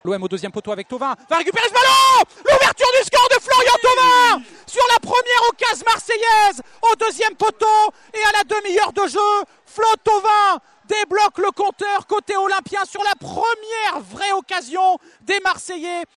Revivez le but de l’attaquant de l’OM commenté  en direct par notre partenaire France Bleu Provence :